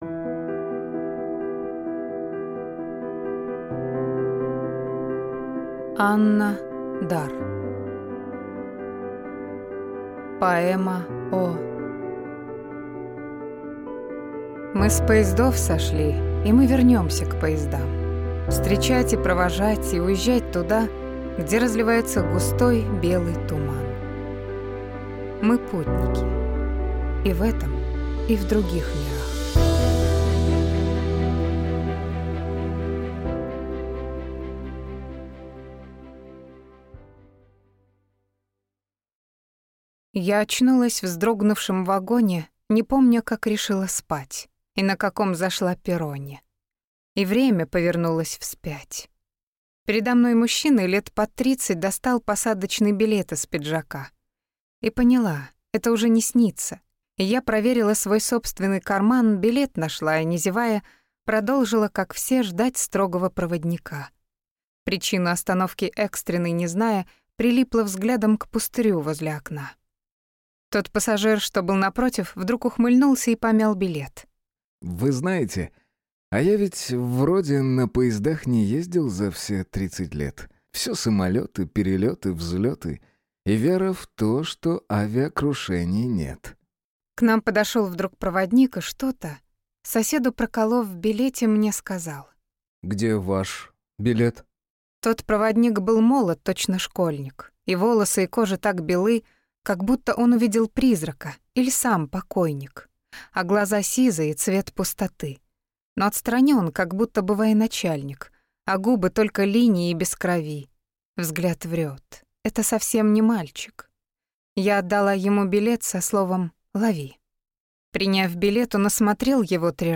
Аудиокнига Поэма О…